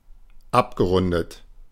Ääntäminen
IPA : /ˈɹaʊnd/